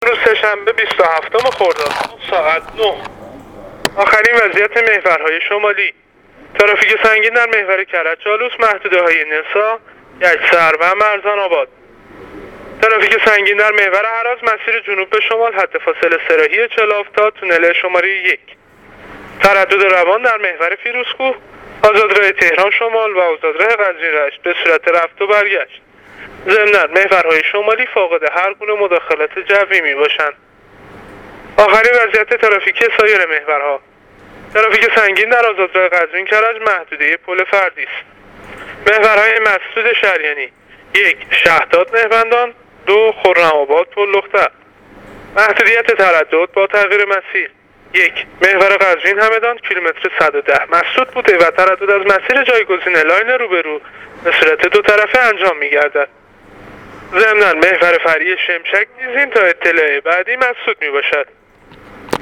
گزارش رادیو اینترنتی از وضعیت ترافیکی جاده‌ها تا ساعت ۹ بیست و هفتم خرداد